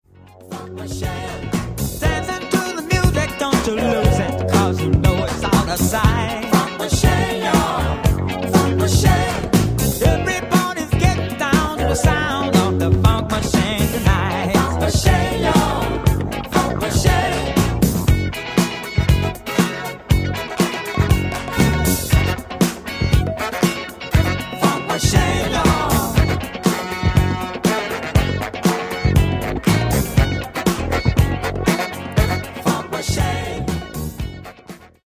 Genere:   Disco | Funky | Miami Sound